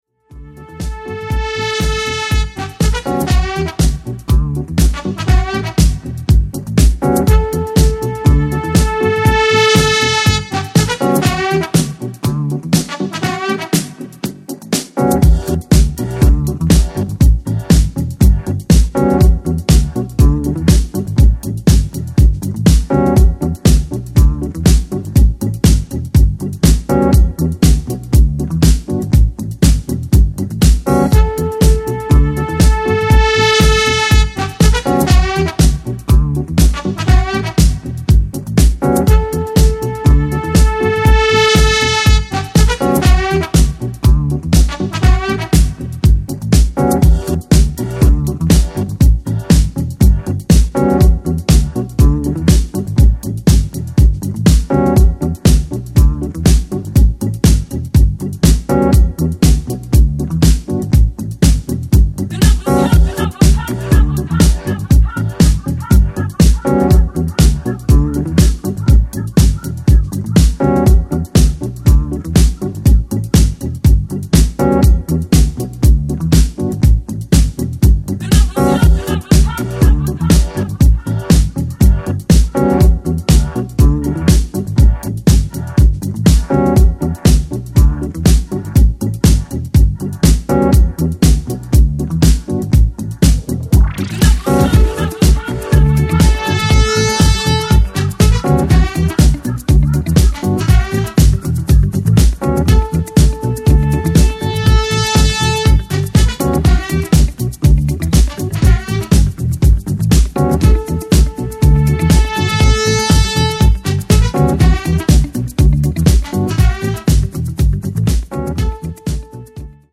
ジャンル(スタイル) NU DISCO